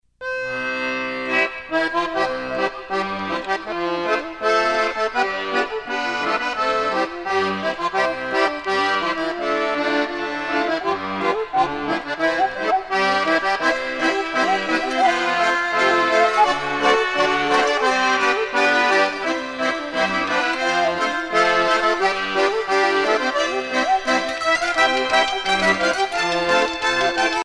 Usage d'après l'analyste gestuel : danse ;
Pièce musicale éditée